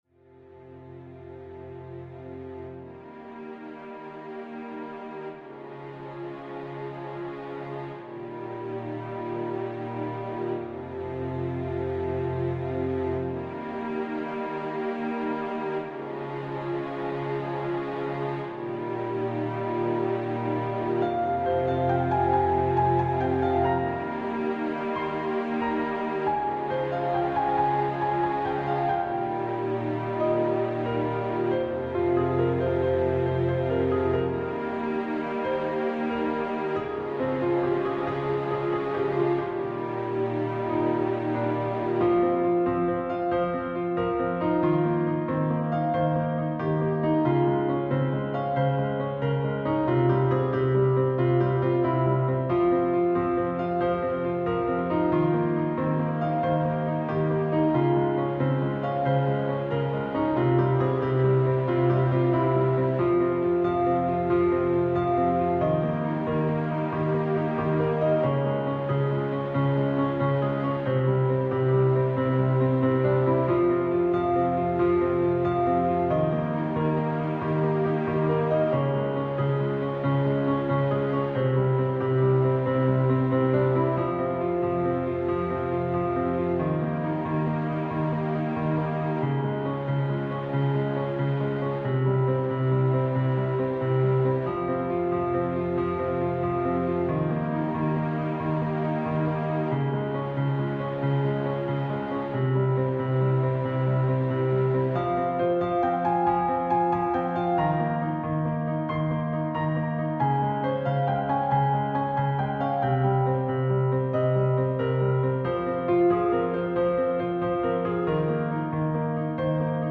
A newage style music